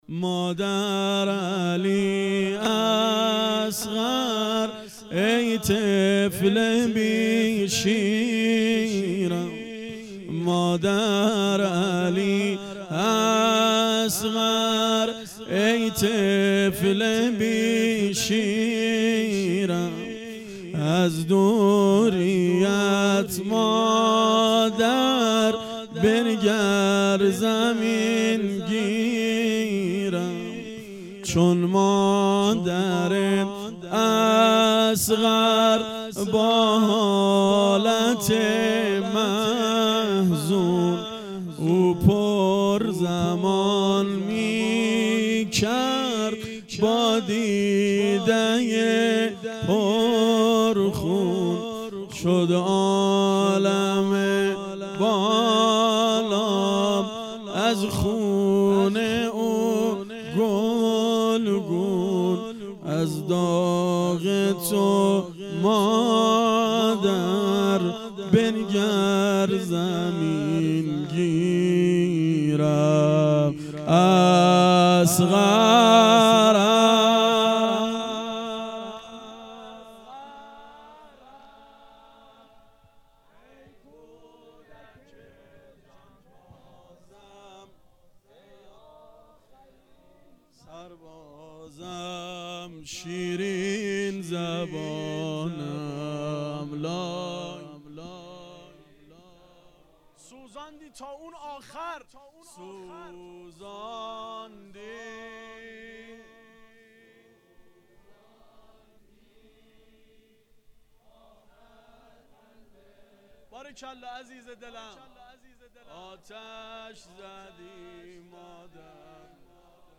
واحد سنتی شب هفتم